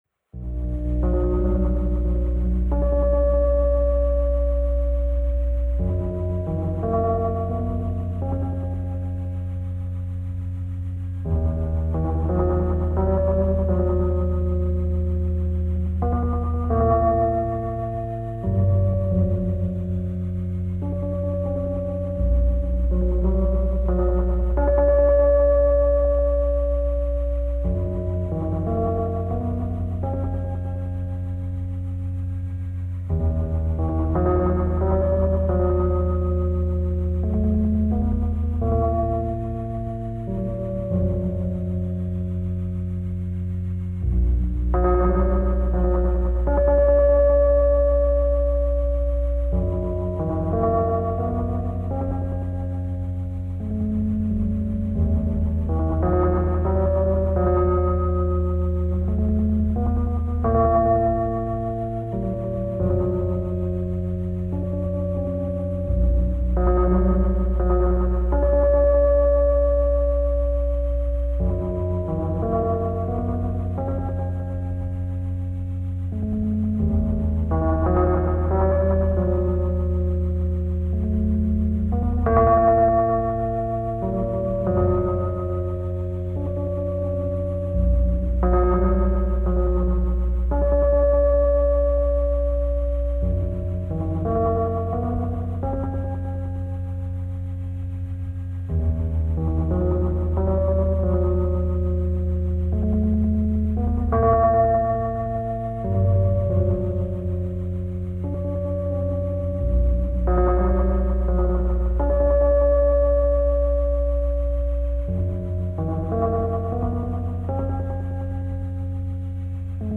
Depressed sad and lonely soundtrack.